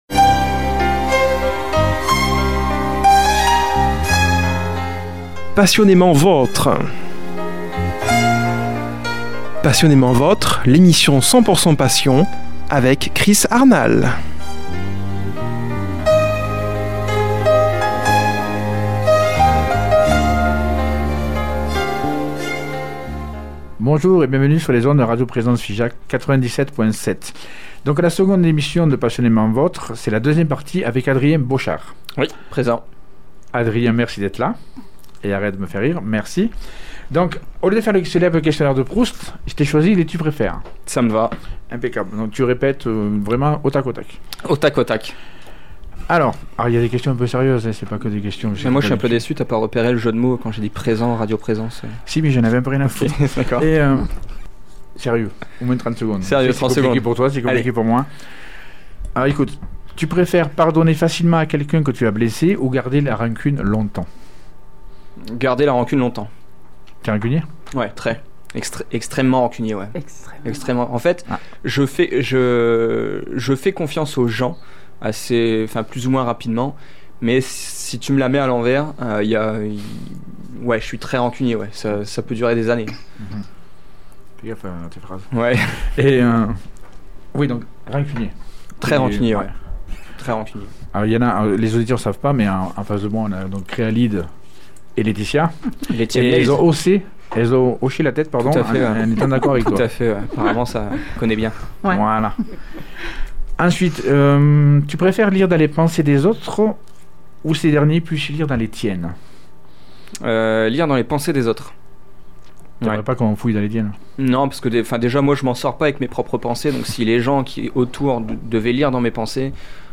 l’entretien au studio